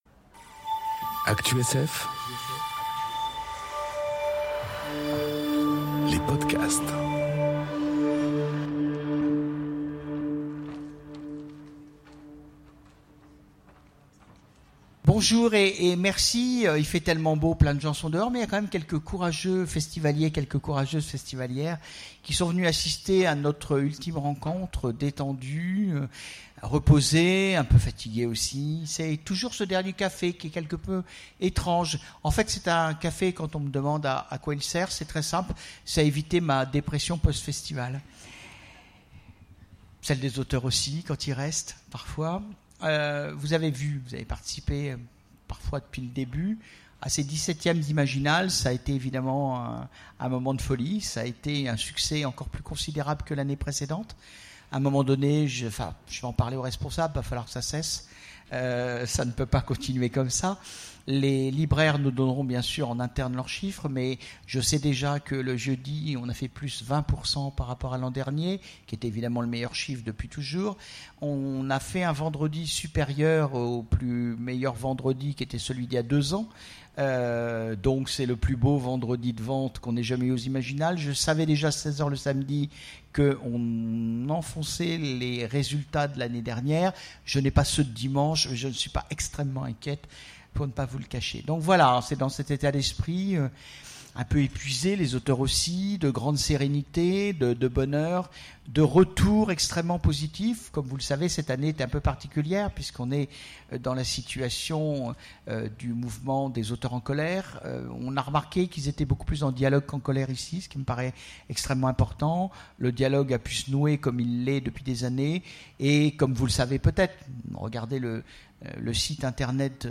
Conférence Un dernier pour la route... Pourquoi être écrivain ? enregistrée aux Imaginales 2018